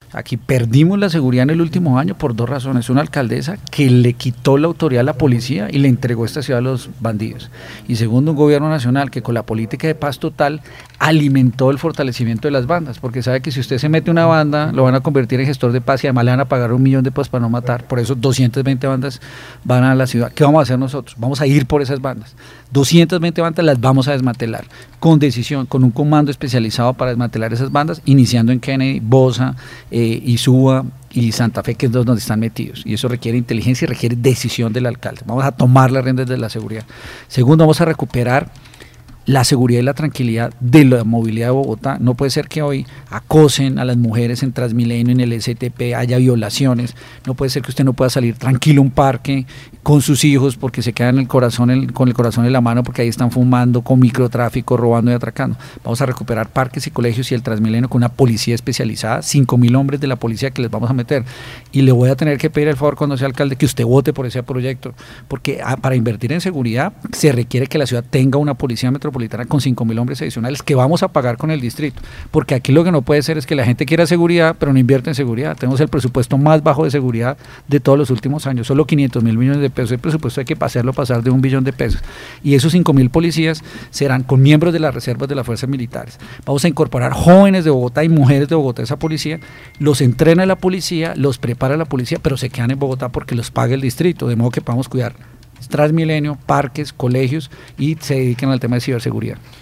El 02 de agosto Radio Panamericana tuvo el honor de recibir como invitado al candidato a la alcaldía de Bogotá Diego Andrés Molano, quien estuvo hablando acerca de la preocupación y las posibles soluciones para la seguridad de la capital.